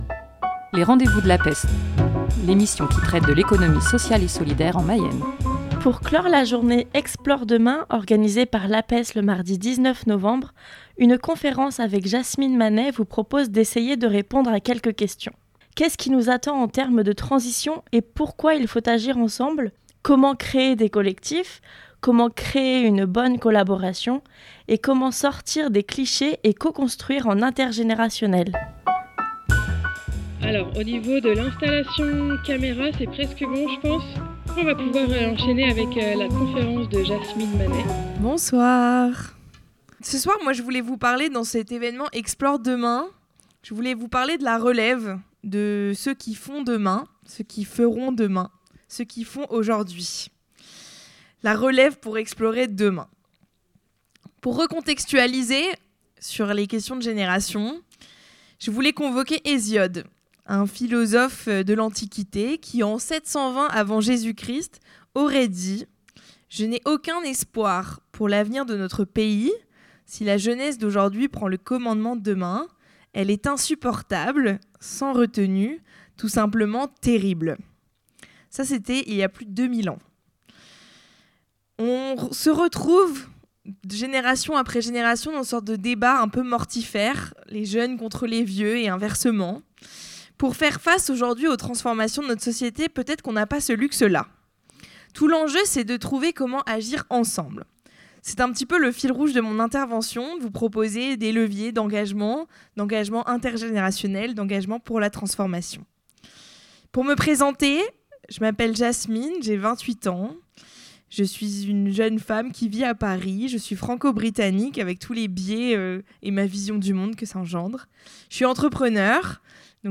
Une conférence pour impulser le changement